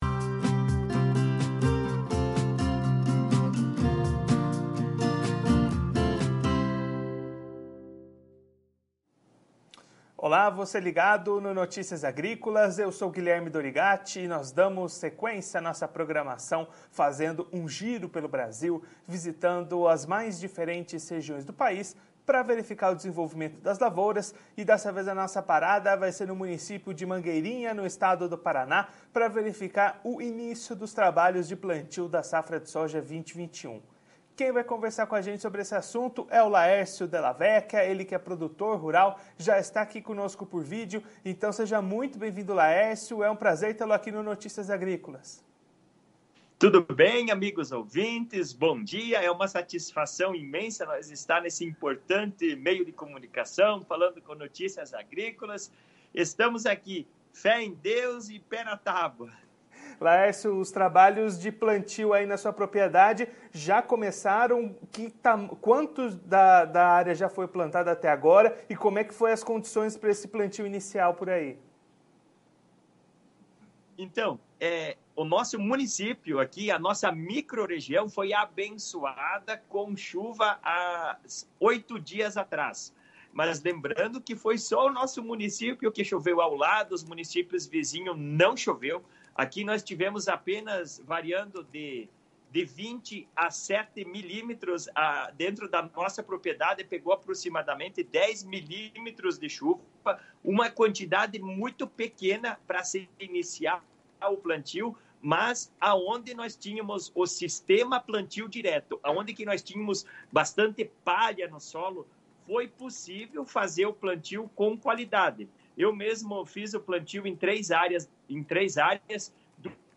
Confira a entrevista completa com o produtor rural de Mangueirinha/PR no vídeo.